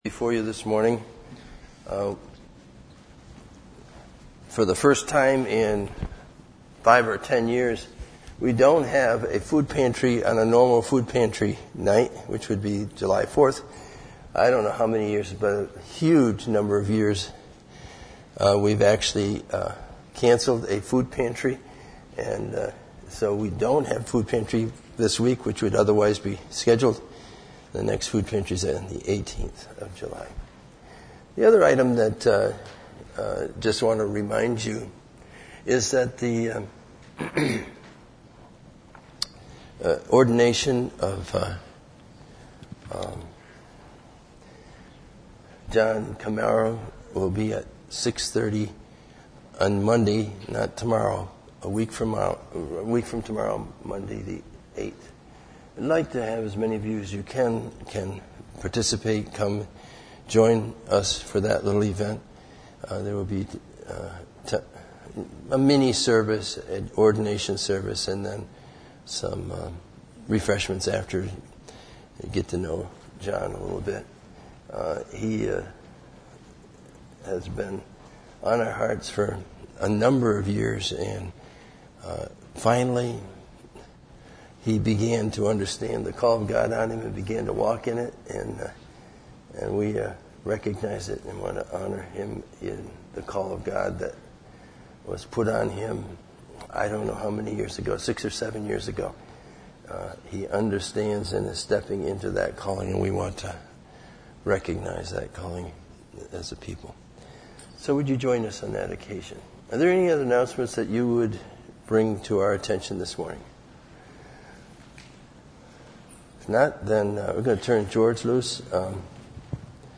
Some older sermons: